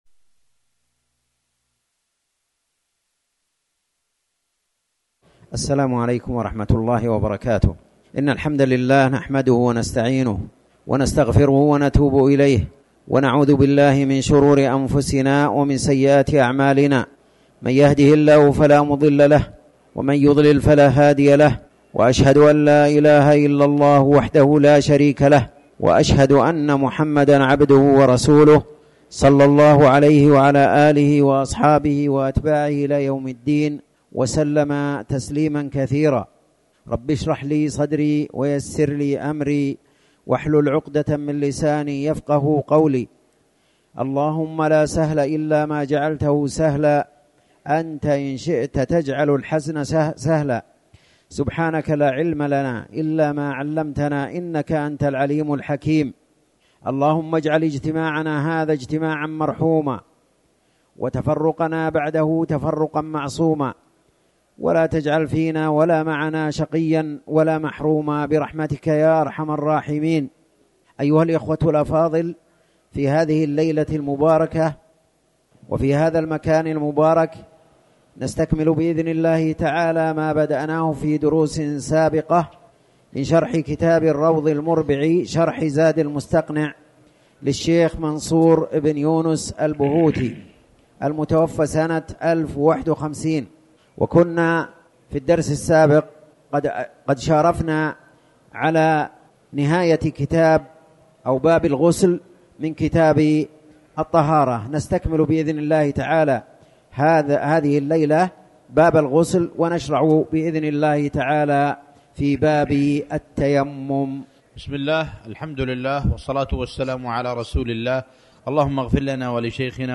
تاريخ النشر ١٠ ربيع الثاني ١٤٤٠ هـ المكان: المسجد الحرام الشيخ